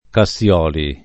[ ka SSL0 li ]